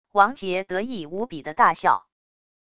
王杰得意无比的大笑wav音频